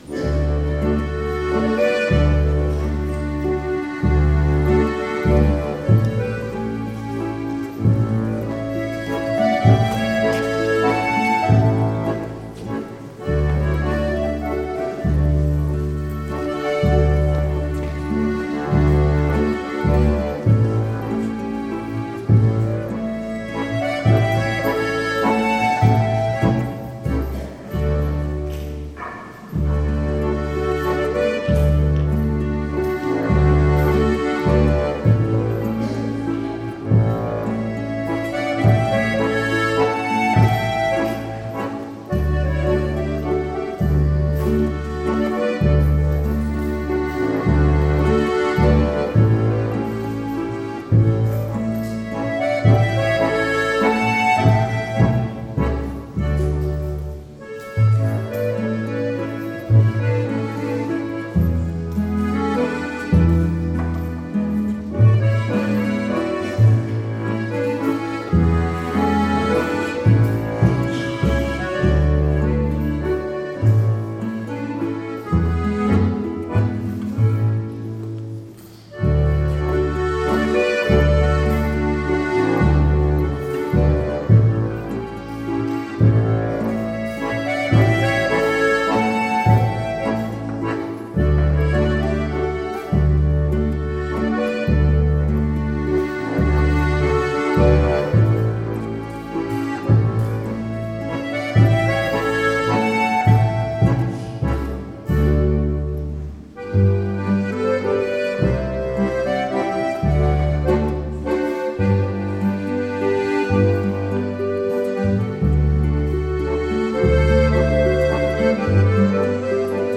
Ziehharmonika, Gitarre und Kontrabass ein sehr angenehmes und familiäres Flair